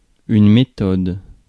Ääntäminen
France: IPA: [me.tɔd]